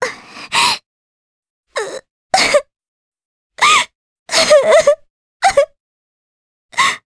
Artemia-Vox_Sad_jp.wav